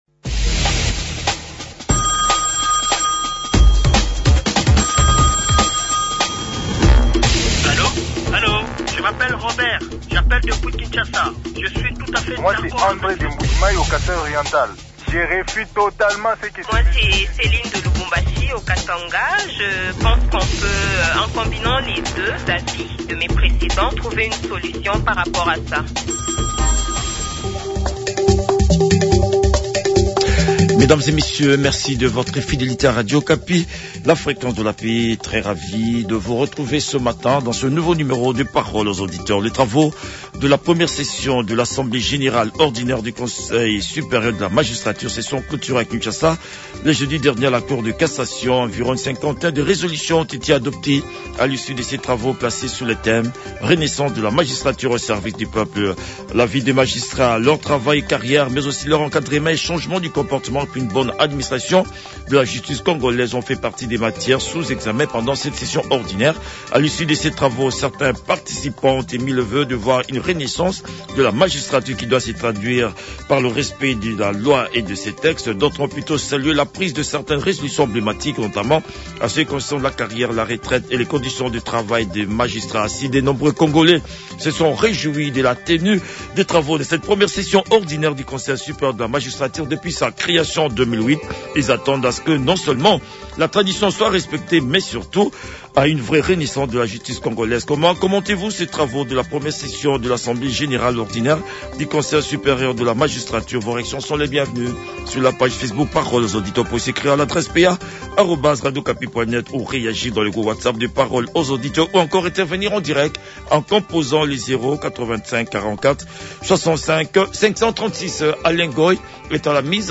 debat avec les auditeurs du magazine Parole aux auditeurs